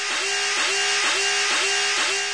Дребезжащий звук сирены.